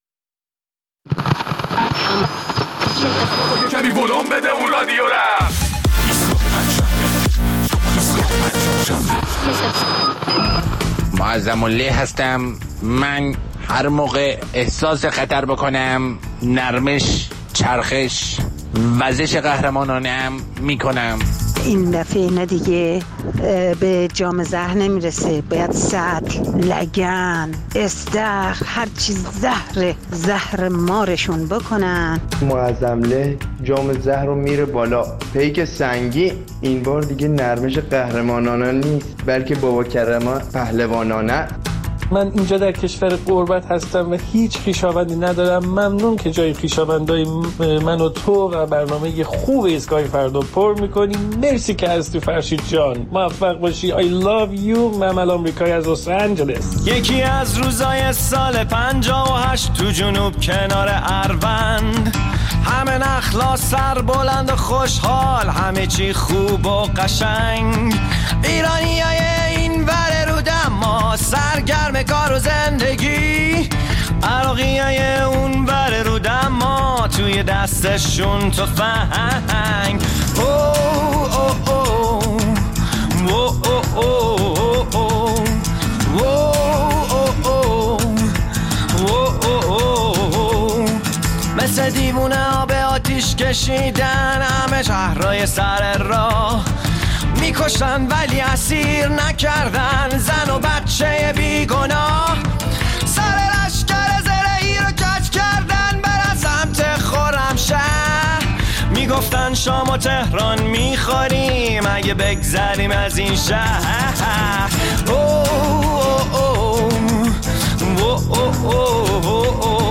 در این ایستگاه‌ پنجشنبه ادامه نظرات شما را درباره شروط ۱۲گانه مارک پومپئو وزیر خارجه آمریکا برای مذاکره مجدد با ایران می‌شنویم.